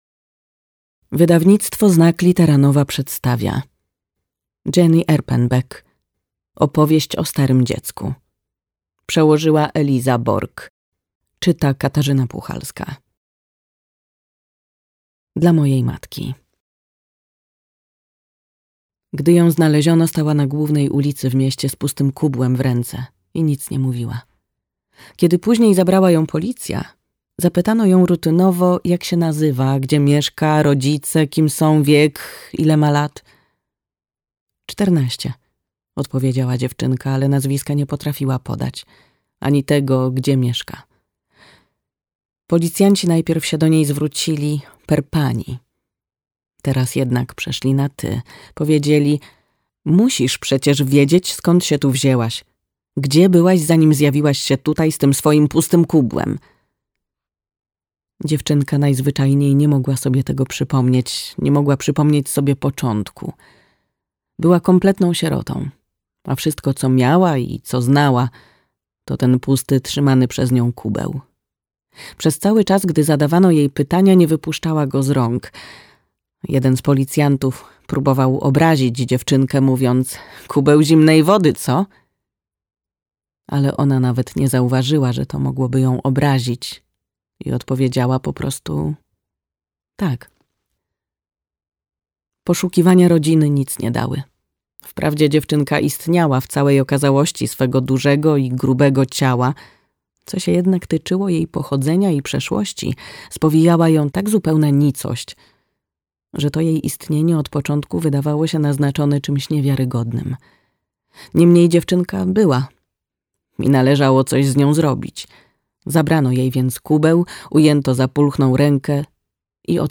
Opowieść o starym dziecku - Jenny Erpenbeck - audiobook + książka